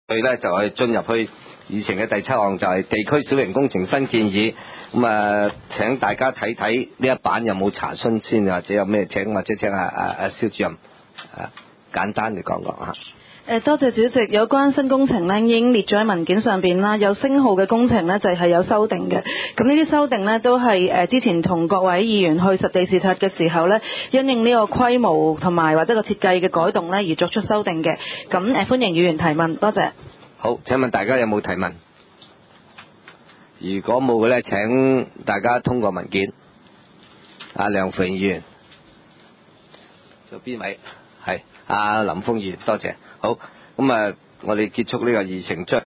第三屆觀塘區議會屬下 環境及生委員會第十八次會議記錄 日 期 : 2010 年 7 月 20 日 ( 星期二 ) 時 間 : 下午 2 時 30 分 地 點 : 九龍觀塘同仁街 6 號觀塘政府合署 3 樓觀塘民政事務處會議室 議 程 討論時間 I. 通過上次會議記錄 0:00:26 II.